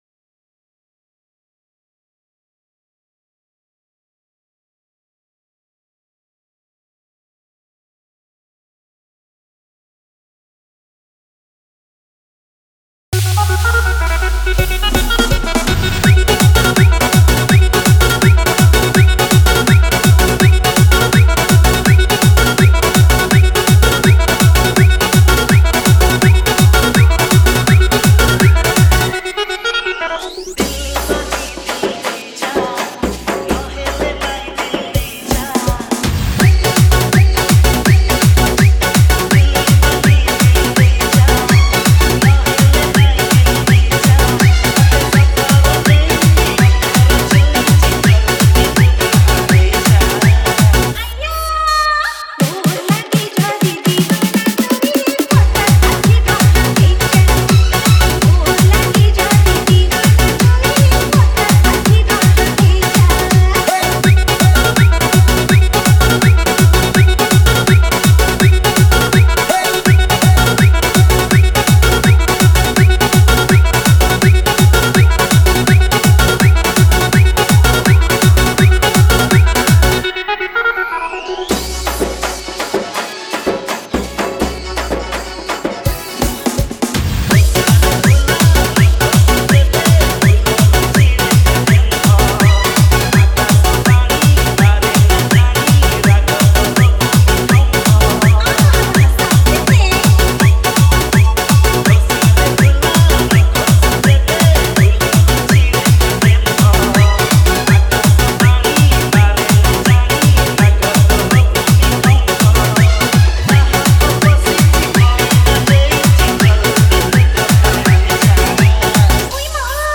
Odia Remix 2021